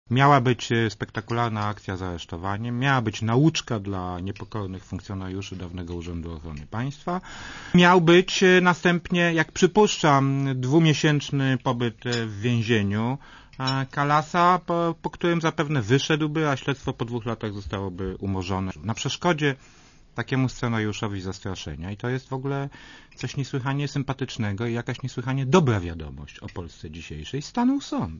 "W moim przekonaniu od dłuższego czasu szykowano jakąś akcję, jakieś przedsięwzięcie, ażeby te osoby, które nie dość, że śmiały pójść do NIK po wyrzuceniu z UOP, ale miały jeszcze śmiałość procesować się z nowym rządem i wygrywać procesy o sposób ich wyrzucenia z ABW, planowano je ukarać. W moim przekonaniu szukano pretekstu" - powiedział Rokita w niedzielę w audycji Radia Zet "Siódmy dzień tygodnia".